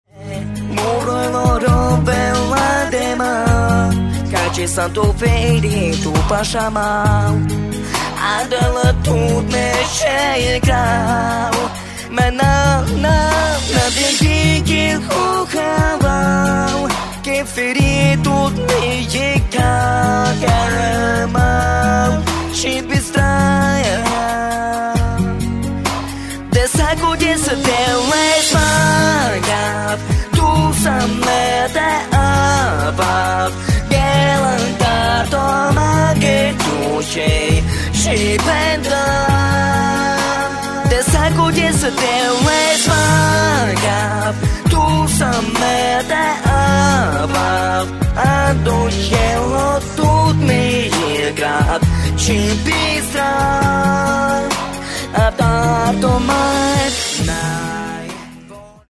Вот самый вредный случай "пения"...